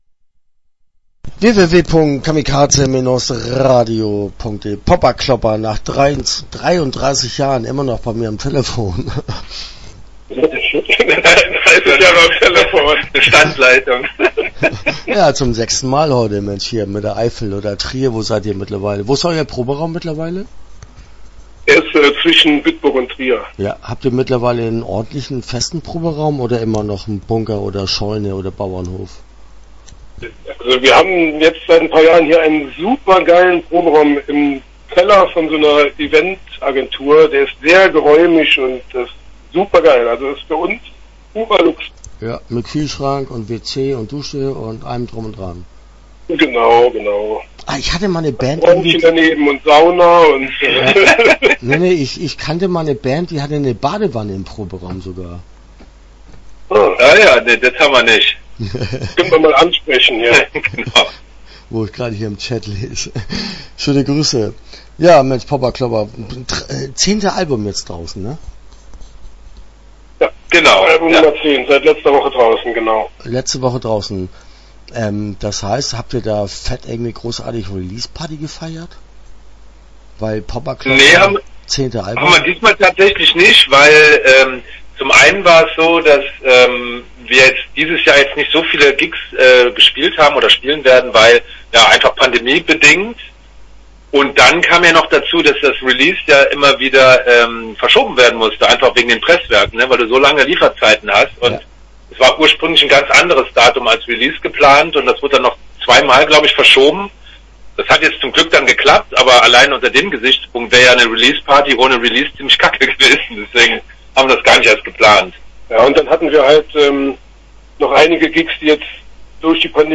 Popperklopper - Interview Teil 1 (13:00)